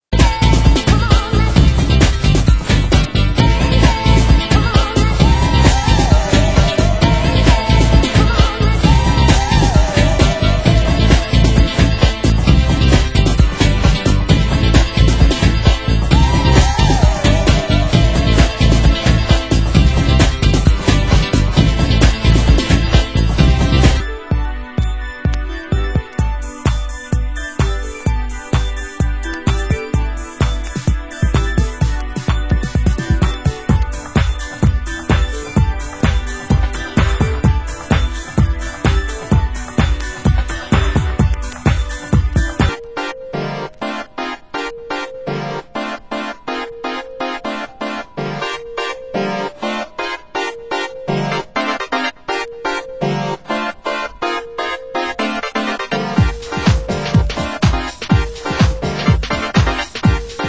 Format: Vinyl 12 Inch
Genre: Hardcore